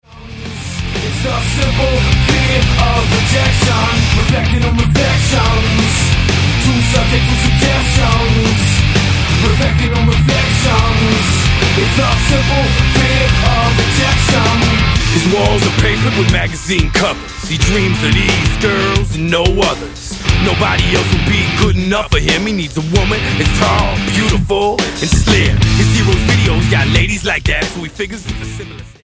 STYLE: Hard Music
Here is a good rap rock album